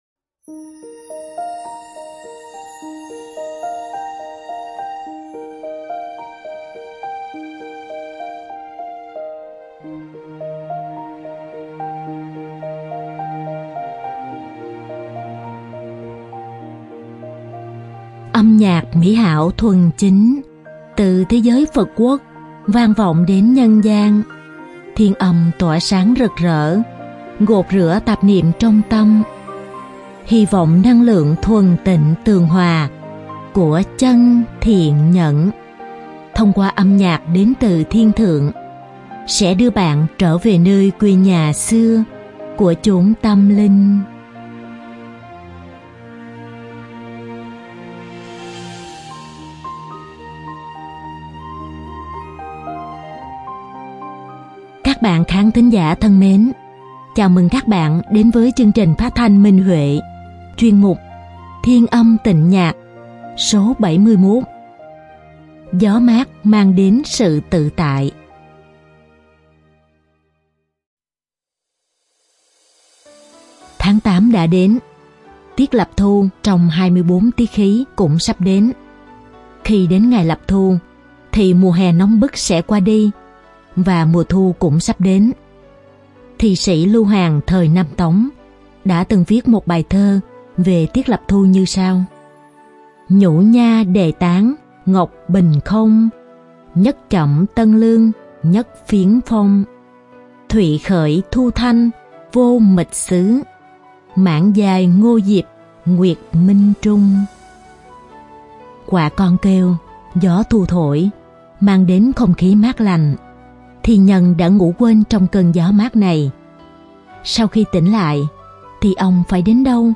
Đơn ca nữ
Đơn ca nam